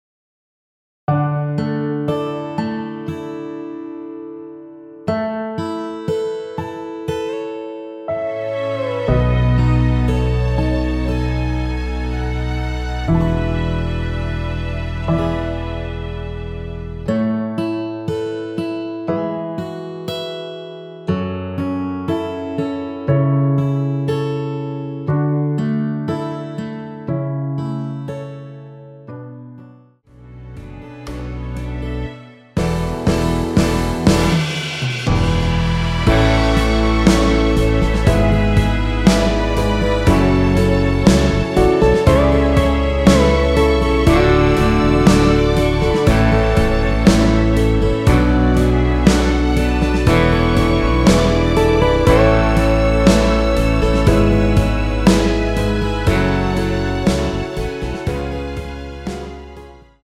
원키에서(+5)올린 MR입니다.
앞부분30초, 뒷부분30초씩 편집해서 올려 드리고 있습니다.
중간에 음이 끈어지고 다시 나오는 이유는